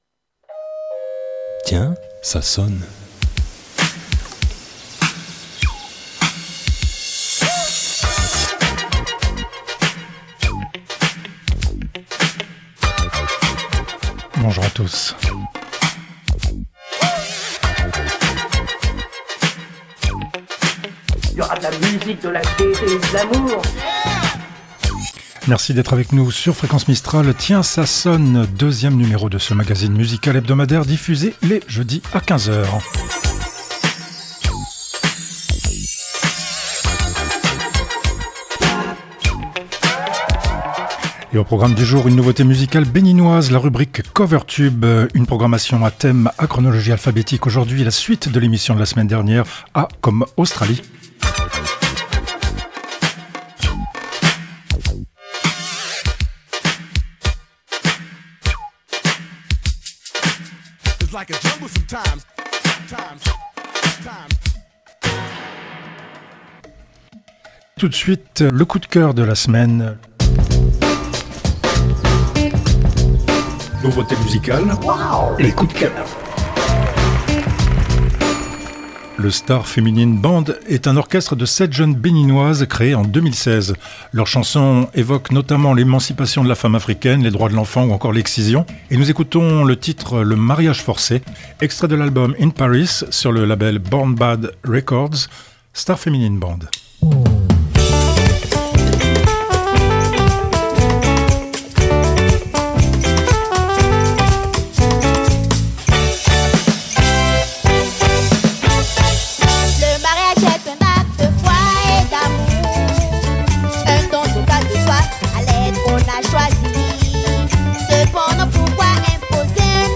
Générique & jingles, voix additionnelles